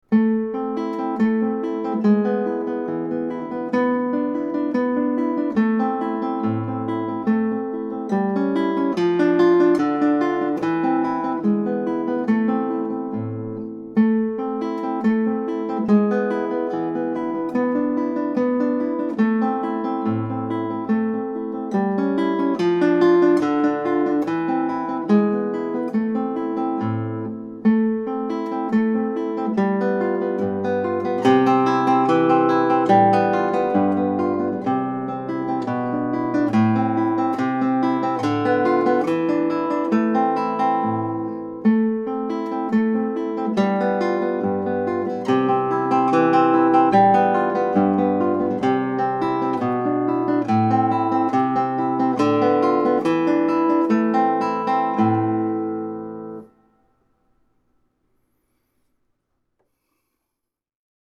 Aguado's Étude in A Minor is full of churning arpeggios and swashbuckling Spanish flavor.
The melody is in the bass, so pluck the bass notes with vigor. Aim for moderato or a peppy andante tempo.
guitar